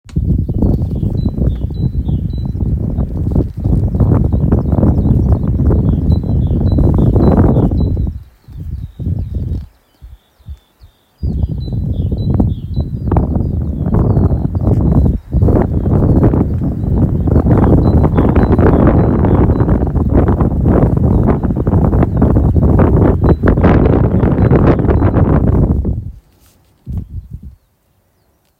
Fuglelyd på morgenen.
Dårlig lyd på opptaket pga vind, men hvilken fugl kan dette være? Synger ofte tidlig på morgenen, og kommer tilbake hvert år.
Dette er en kjøttmeis.